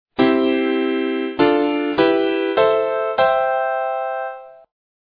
and here is the transposed triad B → E, from the beginning of the theme of G Mahler's adagietto:
Transposed triad from G Mahler's adagietto